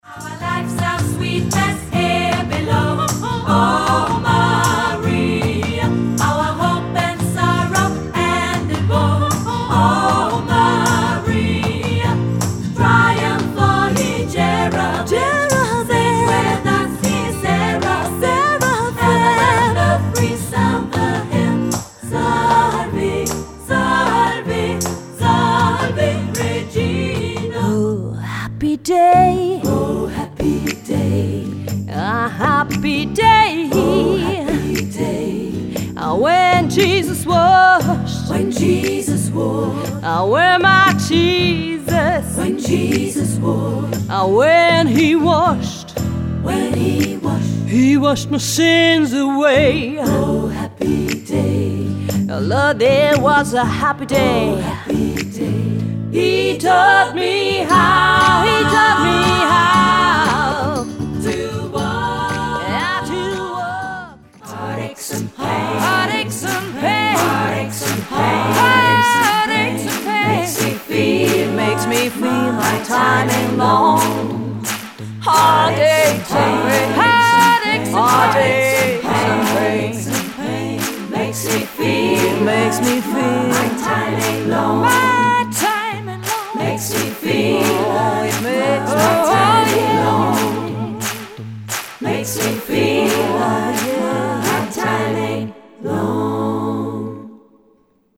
Die bekanntesten traditionellen und modernen Gospelsongs